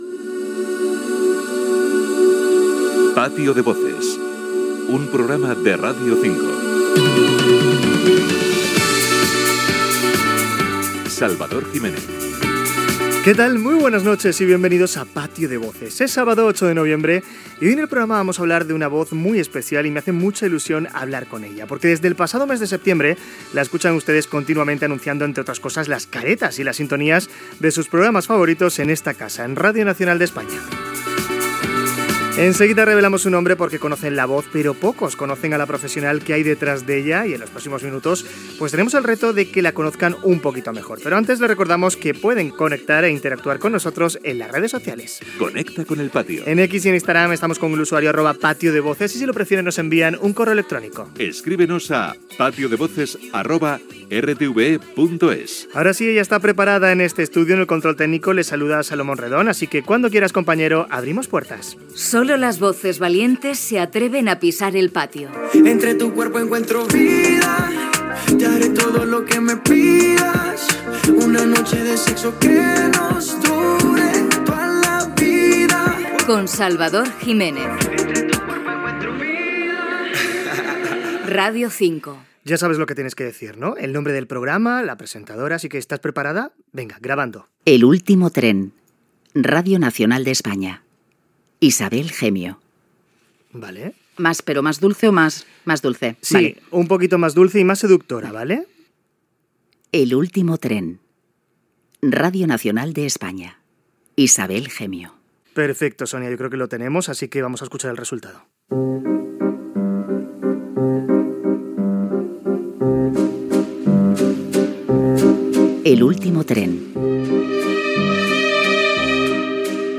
Careta del programa
Gènere radiofònic Entreteniment